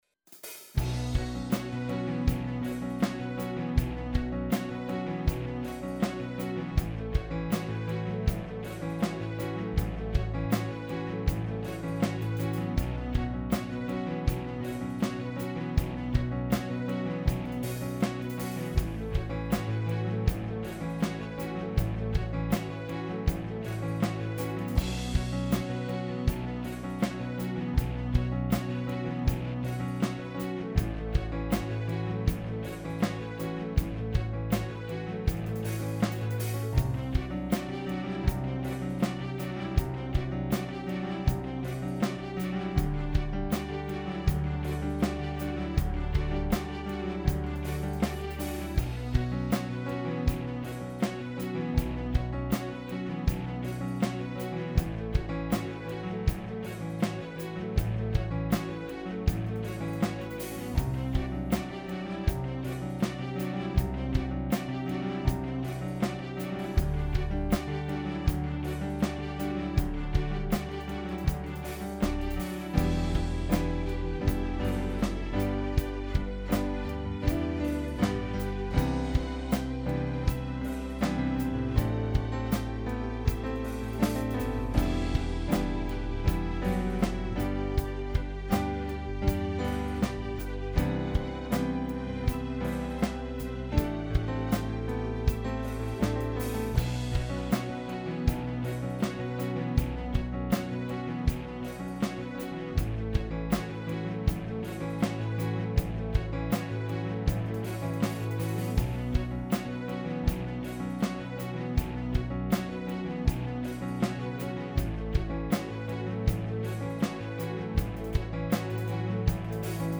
Instrumental. The string motif was a happy accident - it was supposed to be a clavinet, but I selected the wrong patch. Wow are the strings 100x better.